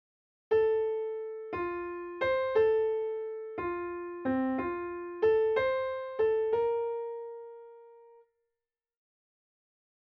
6/8 example
Beethoven used it in the last movement of his sixth symphony (known as the “Pastorale” symphony), and named the movement “Shepherd's Song…”